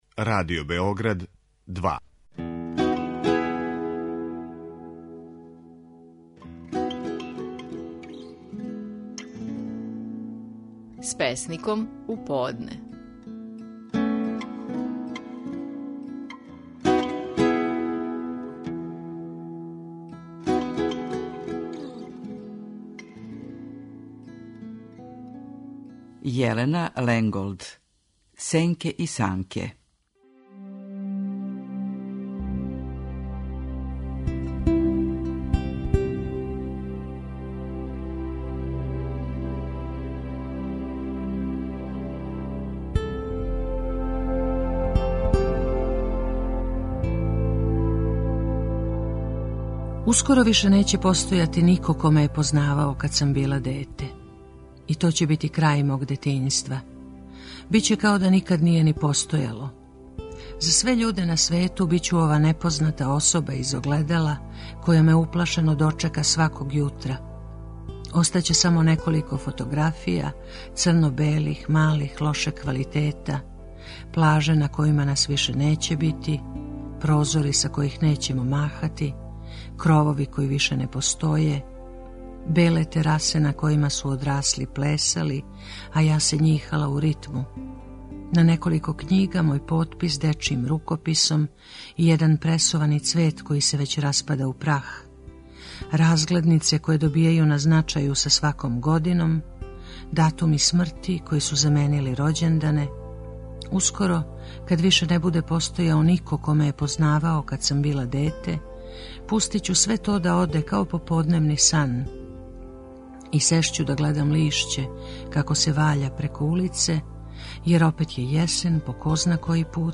Наши најпознатији песници говоре своје стихове
„Сенке и санке" - назив је песме коју говори песникиња Јелена Ленголд.